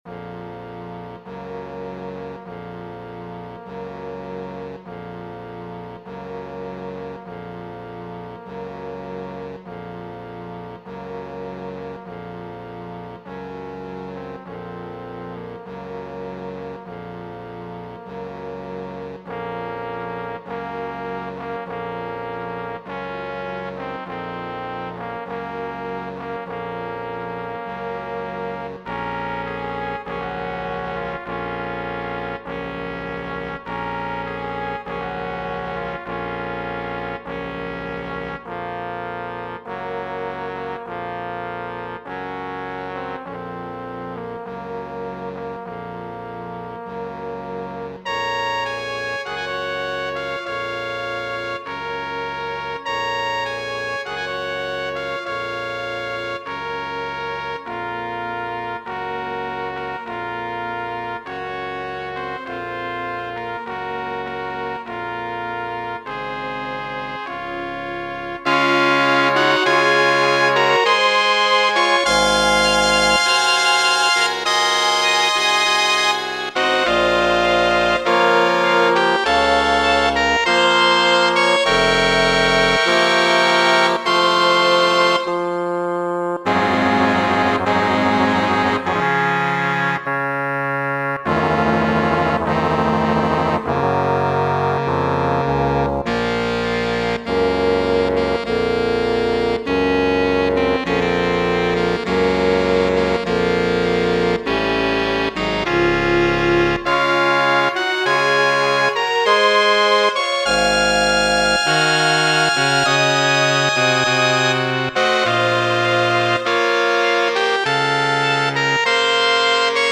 Muziko
funebre.mp3